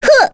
Rodina-Vox_Attack4.wav